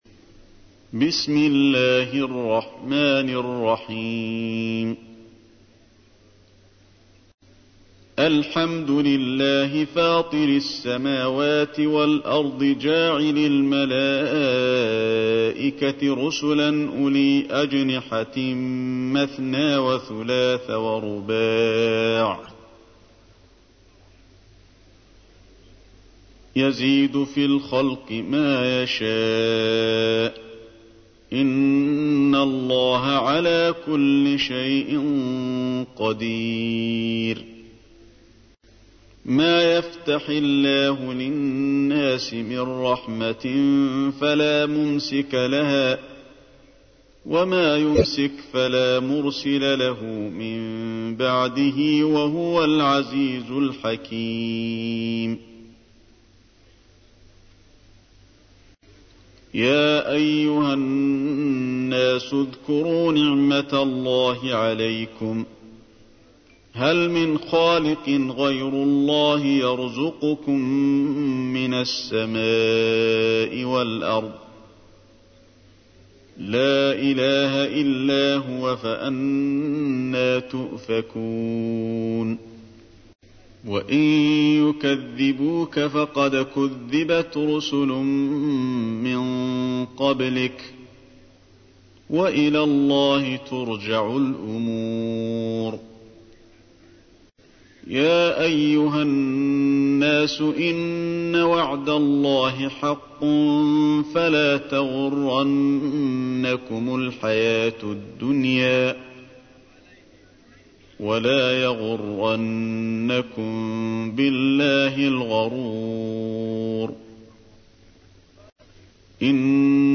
تحميل : 35. سورة فاطر / القارئ علي الحذيفي / القرآن الكريم / موقع يا حسين